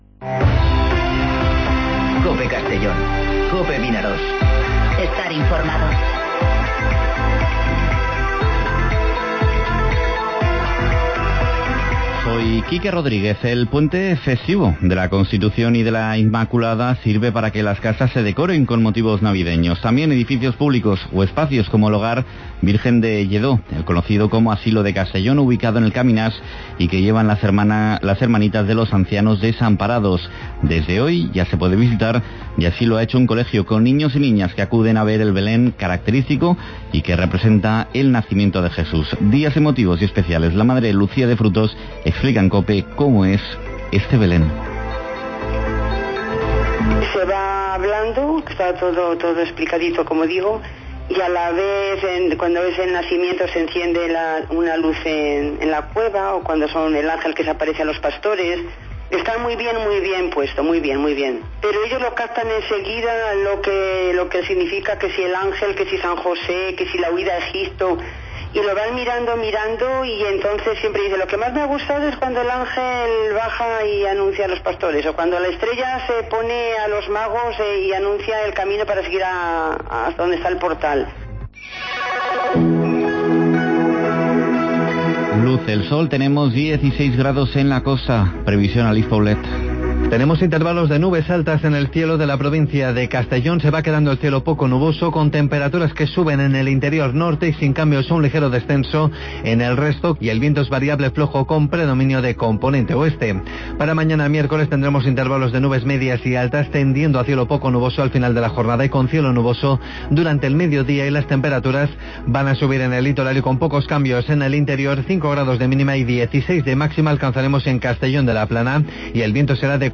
Informativo Mediodía COPE en Castellón (10/12/2019)